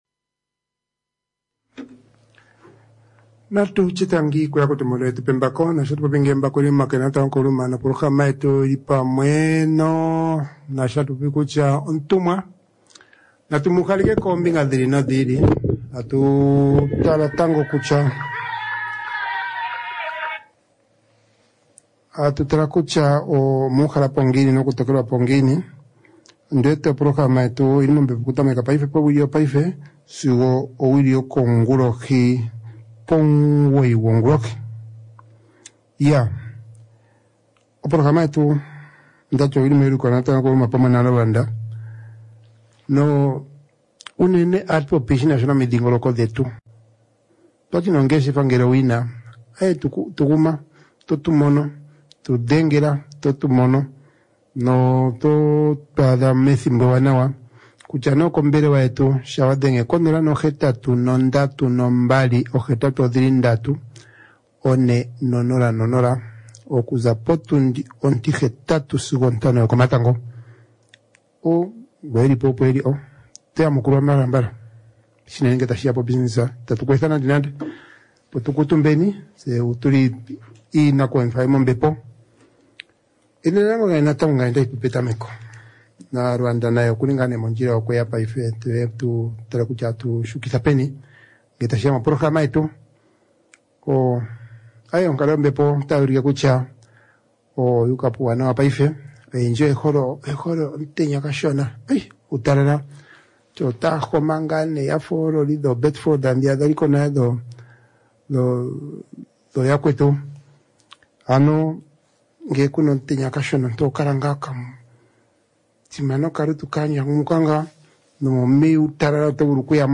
Omweenda ota kala Doctor Bernard Haufiku, oye taka fatulula moule ombinga yotunhila tuu ei.